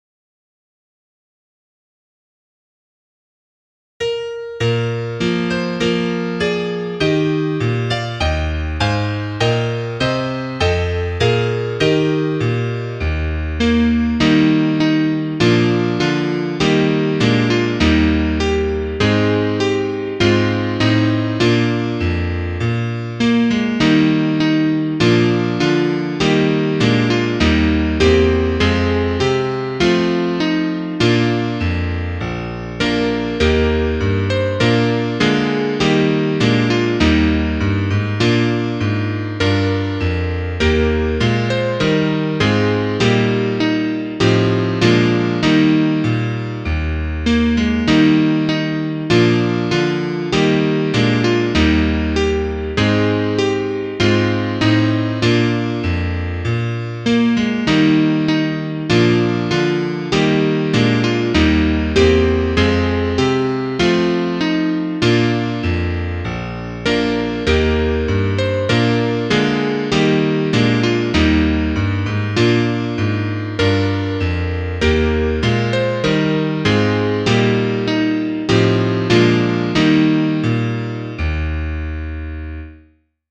Klarinette 1